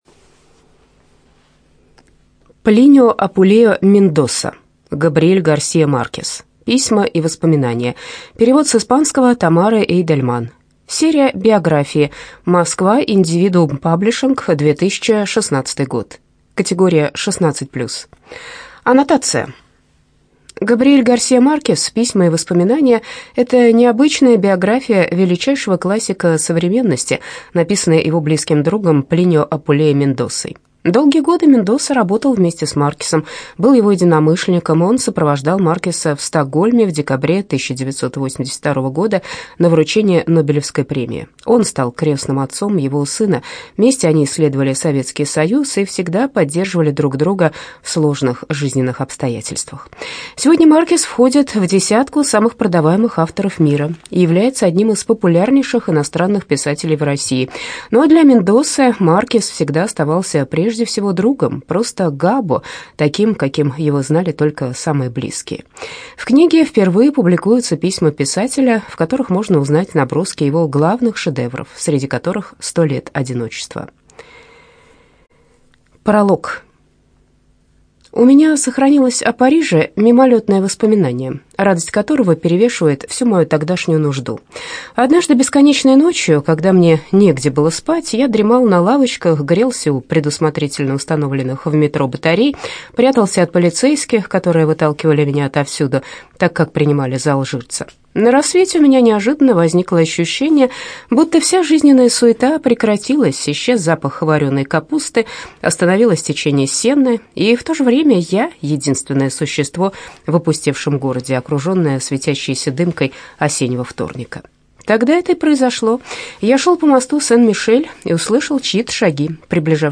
ЖанрБиографии и мемуары, Переписка
Студия звукозаписиЛогосвос